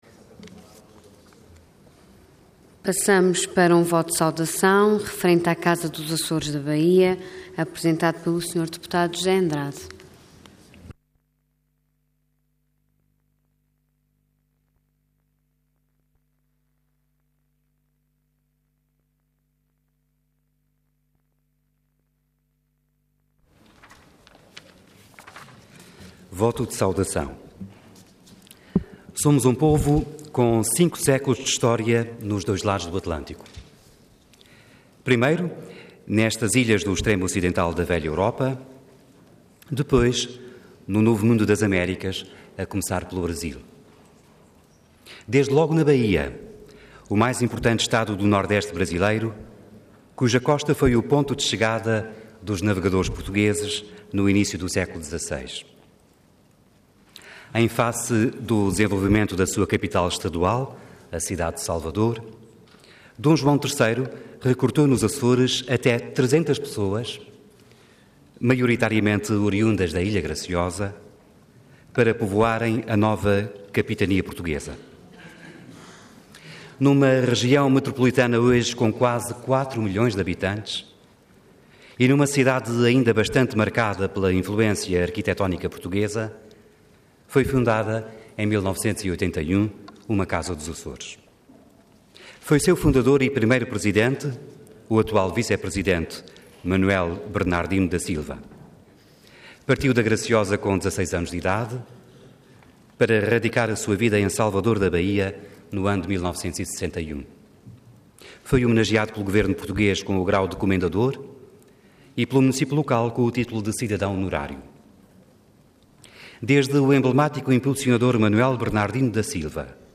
Intervenção Voto de Saudação Orador José Andrade Cargo Deputado Entidade PSD